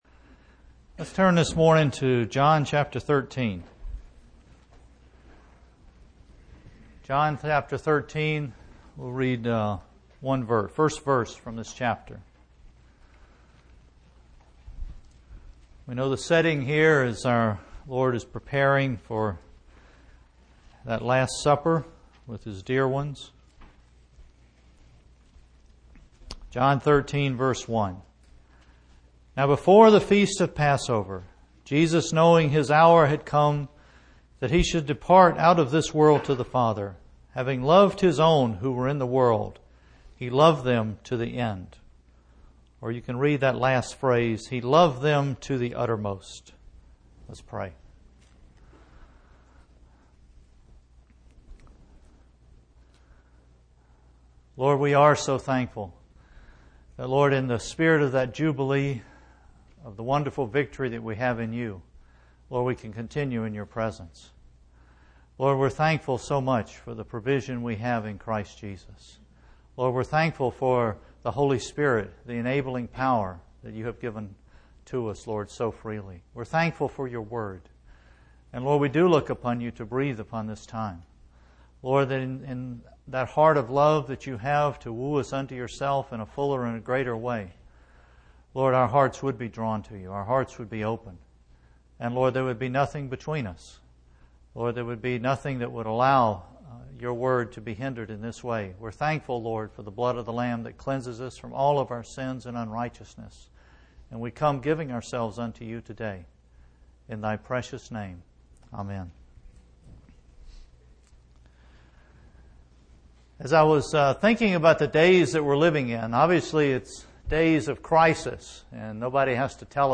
US Stream or download mp3 Summary We often take the love of Christ for granted. This message is an exhortation to remember the height and depth and width and breadth of the love of Christ for us.